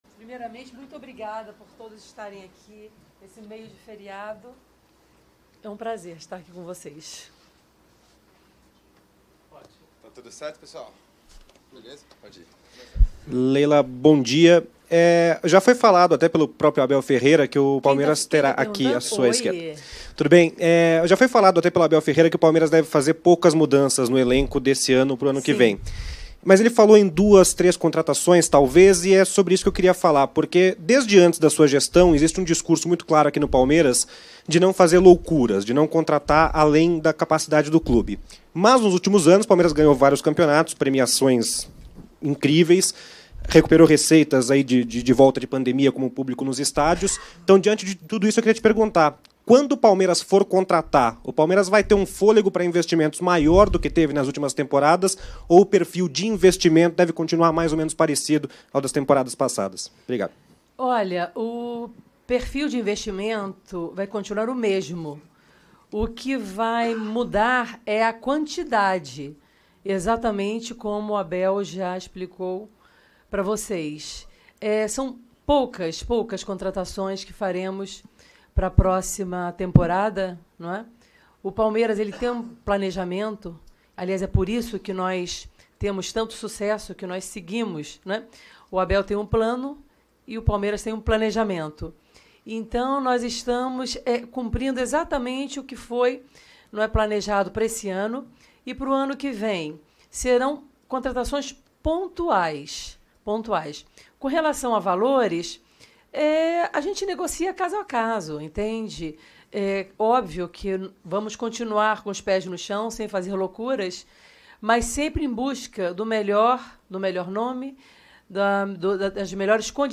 Já projetando o ano de 2023, a presidente Leila Pereira concedeu entrevista coletiva nesta segunda-feira (14), na sala de imprensa da Academia de Futebol
COLETIVA-_-LEILA-PEREIRA.mp3